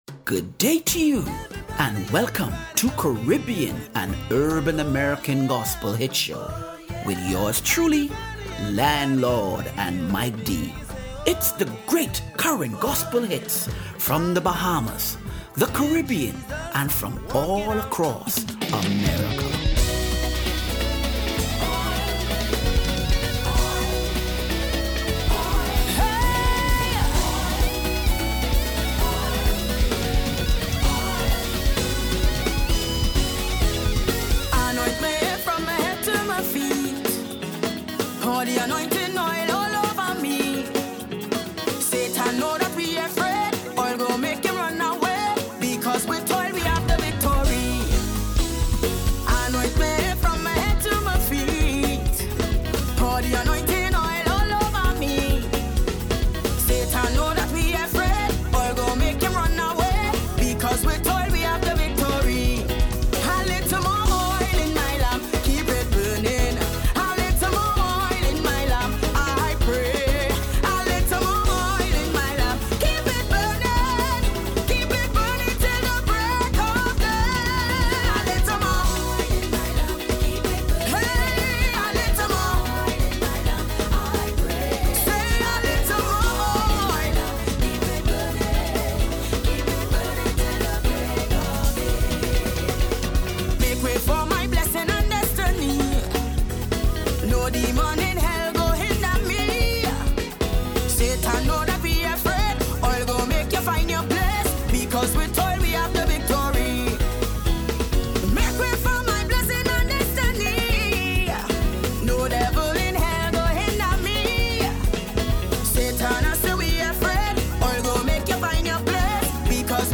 Caribbean and Urban American Gospel Hits - June 29 2025